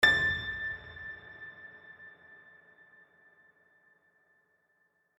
sounds / HardPiano / a5.mp3